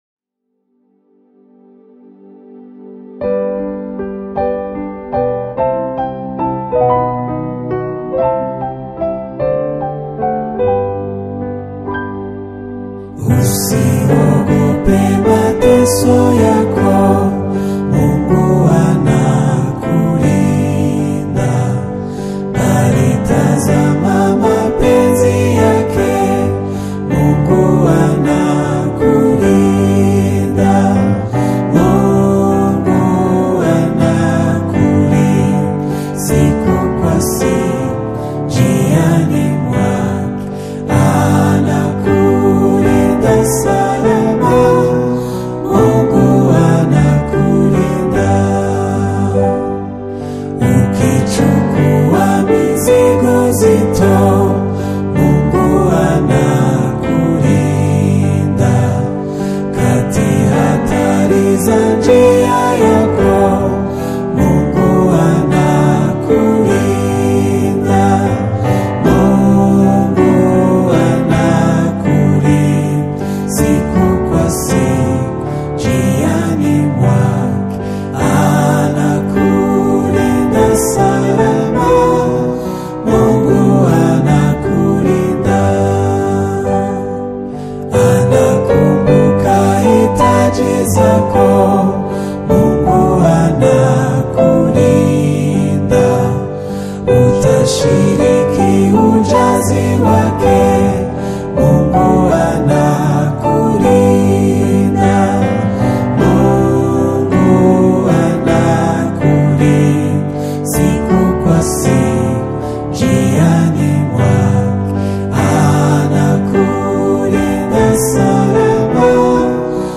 worship
serene and spiritually uplifting single